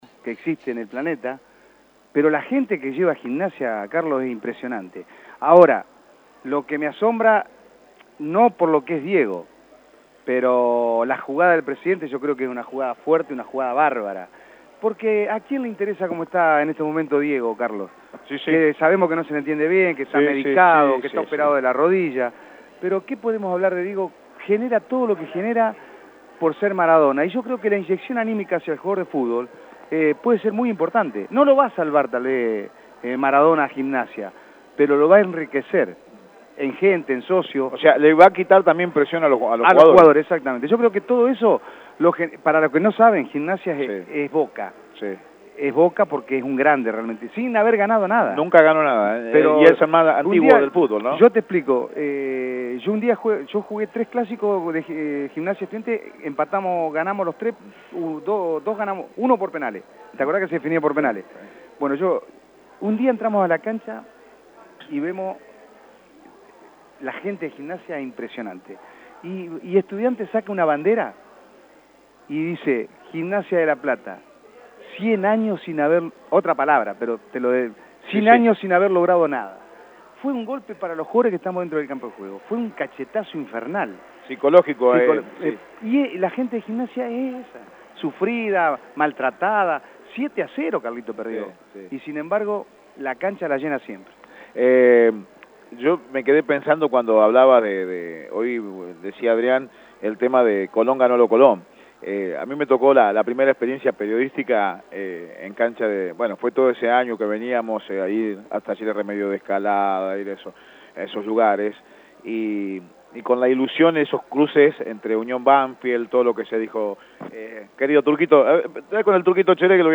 En la jornada de hoy, Radio Eme mudó parte de su programación a la Estación Belgrano en donde se está llevando adelante la Feria Del Libro número XXVI.